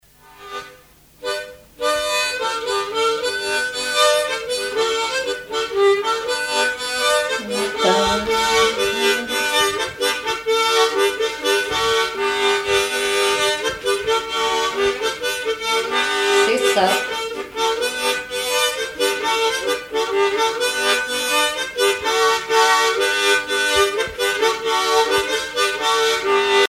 Marche à l'harmonica
Saint-Avé
Chansons traditionnelles